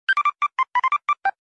neru Meme Sound Effect